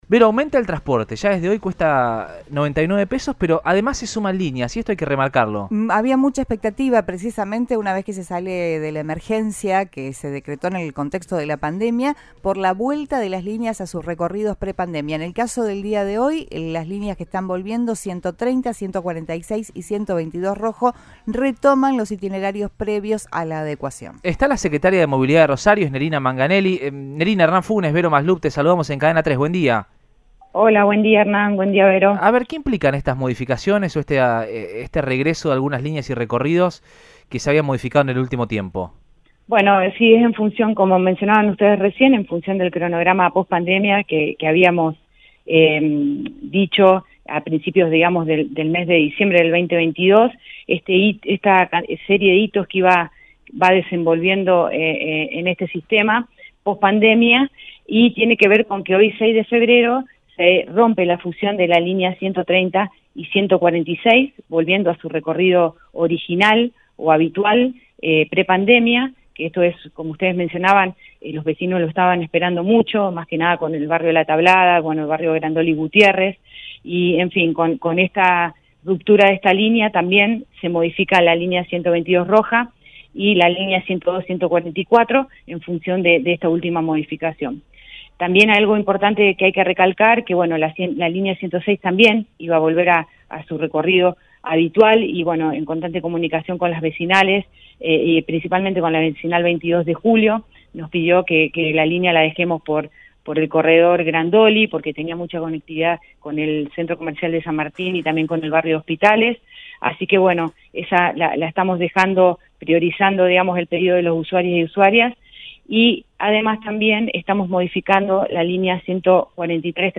Nerina Manganelli, secretaria de Movilidad de Rosario, brindó una entrevista a Radioinforme 3, por Cadena 3 Rosario, y destacó los cambios que se están implementando.